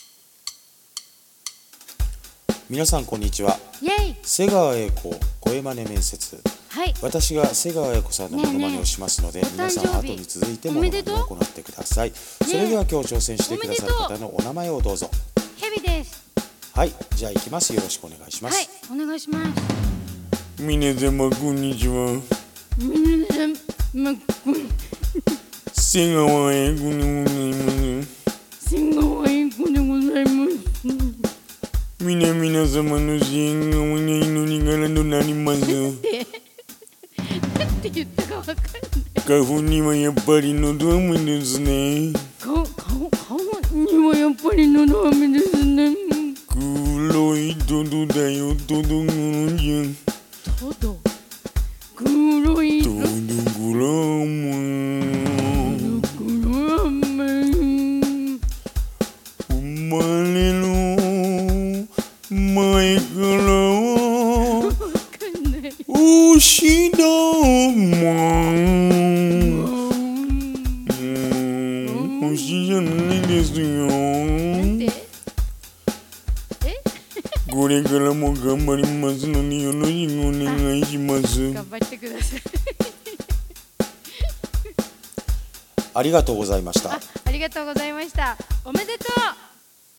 瀬川瑛子声マネ面接 🙃🐍